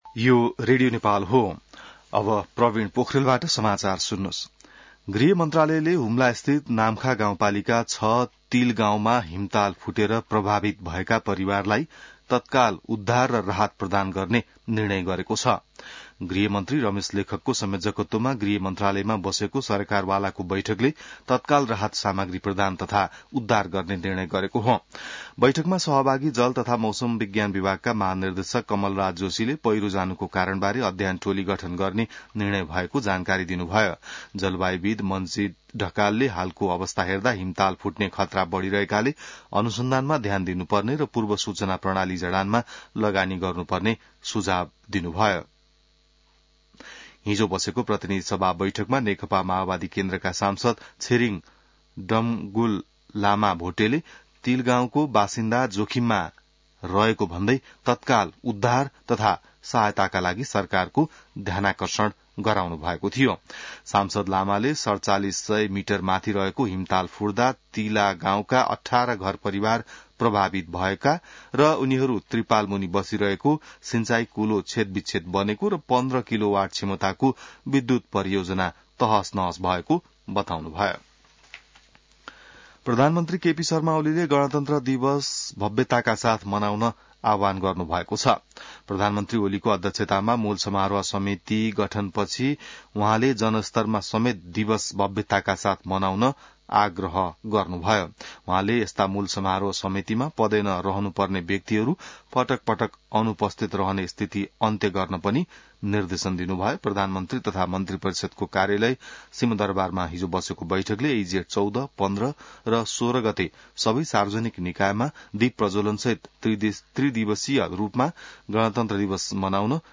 बिहान ६ बजेको नेपाली समाचार : ६ जेठ , २०८२